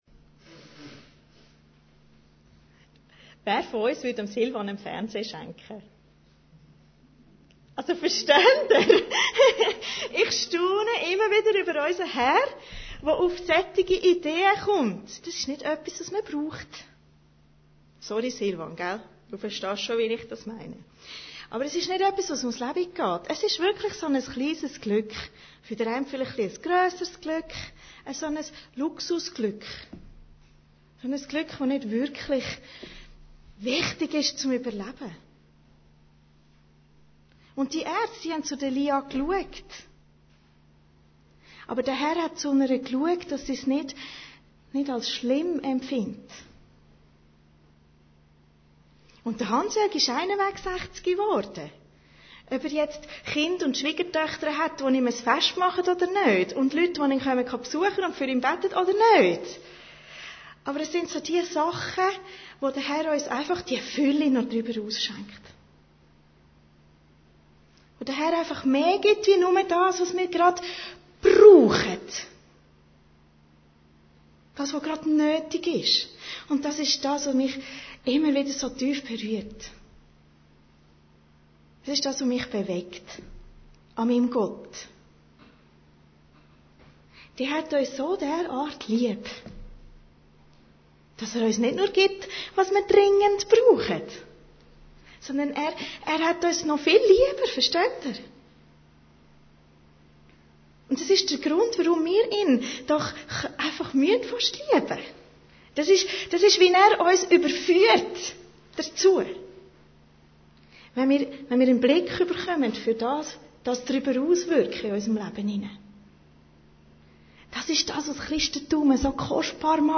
Predigten Heilsarmee Aargau Süd – Sendscheiben an Sardes